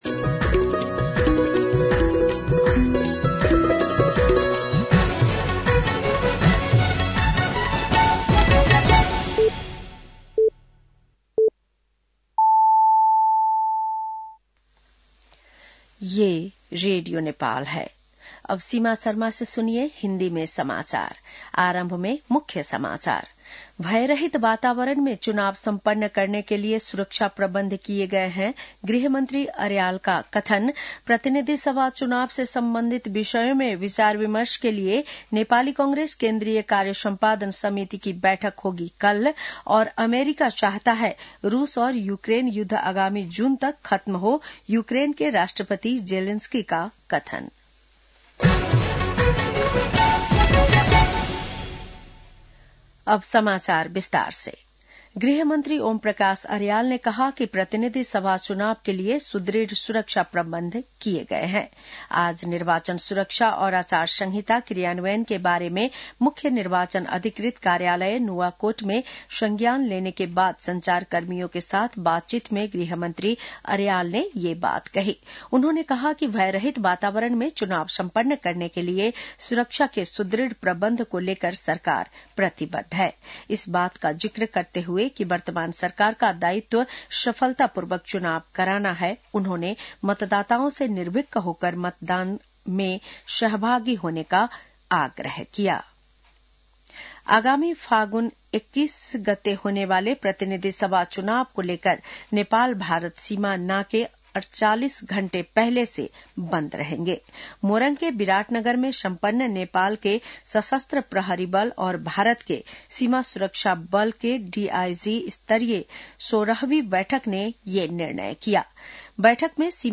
बेलुकी १० बजेको हिन्दी समाचार : २४ माघ , २०८२
10-pm-news-1-1.mp3